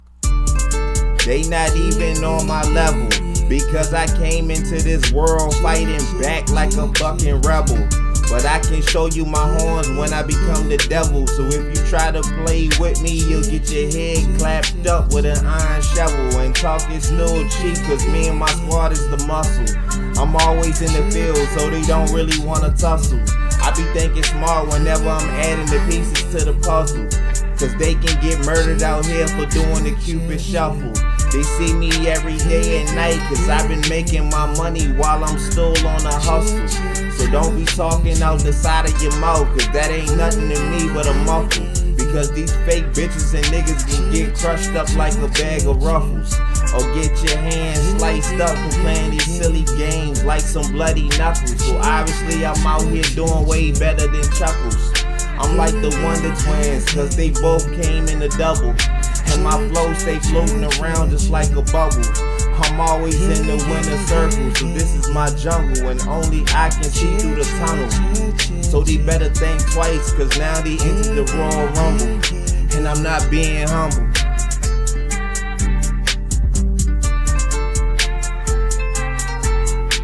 Rap
banger freestyle